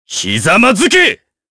Kain-Vox_Skill1_jp.wav